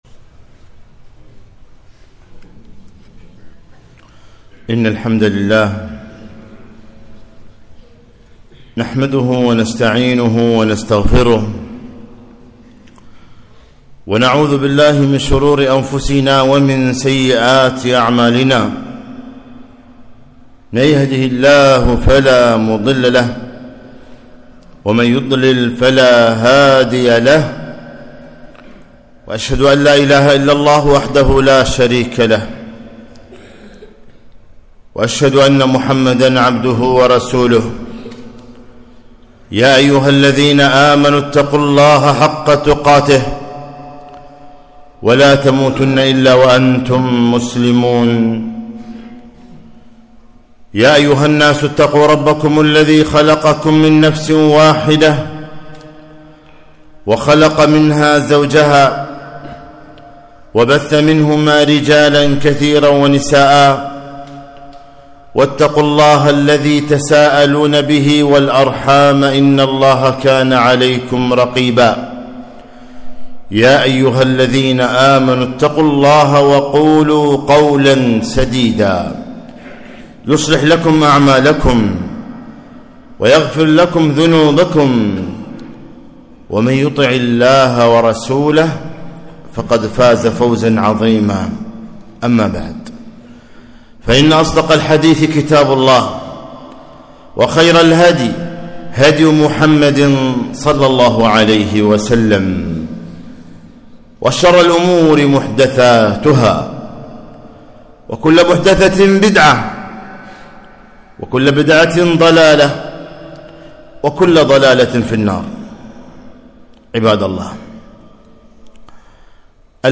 خطبة - ( استحيوا من الله حق الحياء )